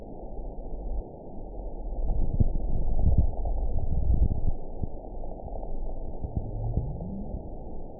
event 920468 date 03/27/24 time 01:26:29 GMT (1 year, 9 months ago) score 7.65 location TSS-AB03 detected by nrw target species NRW annotations +NRW Spectrogram: Frequency (kHz) vs. Time (s) audio not available .wav